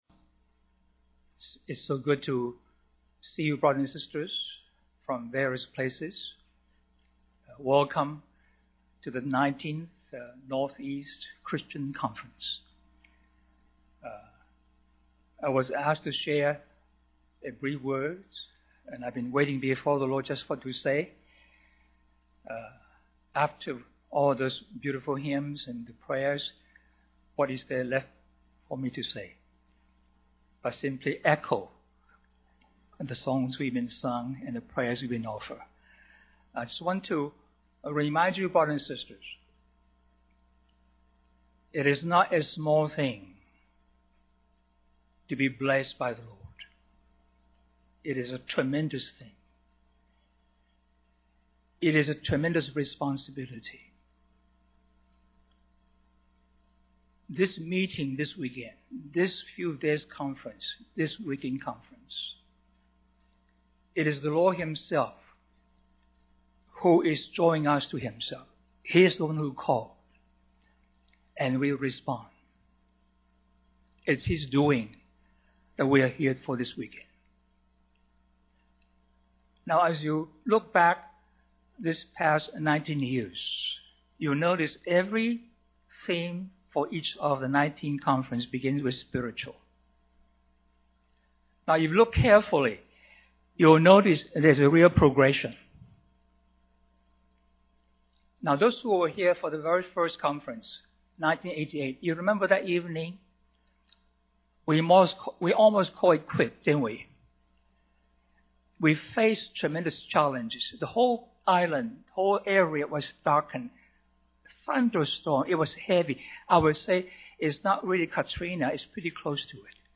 2006 Harvey Cedars Conference Stream or download mp3 Summary Opening comments for the 2006 Harvey Cedars Christian Conference.